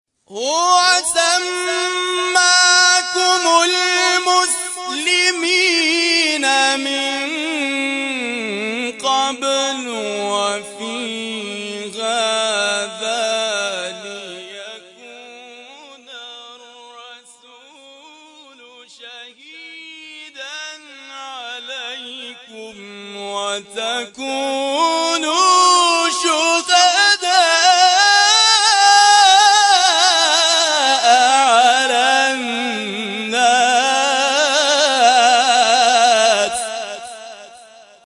گروه شبکه اجتماعی: نغمات صوتی از تلاوت قاریان بین‌المللی و ممتاز کشور که به تازگی در شبکه‌های اجتماعی منتشر شده است، می‌شنوید.